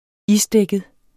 Udtale [ ˈisˌdεgəð ]